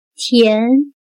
/Tián/dulce